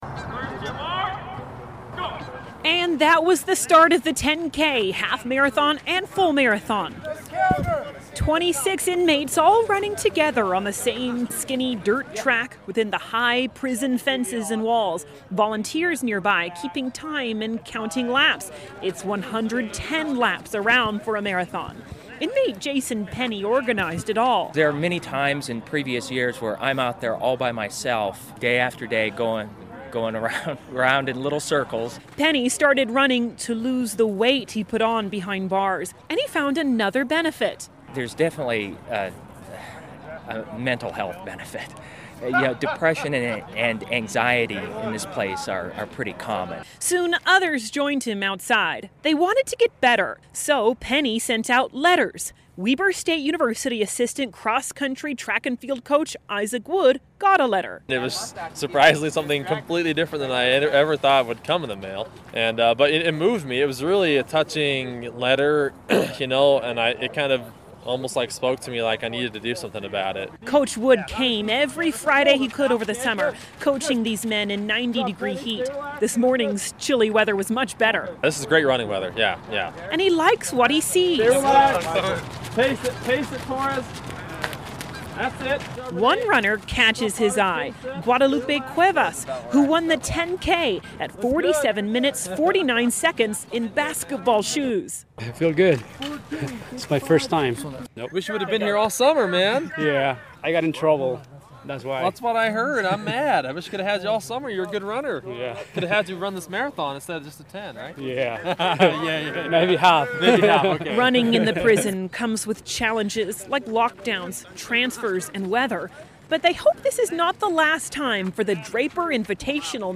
reports from the Utah State Prison.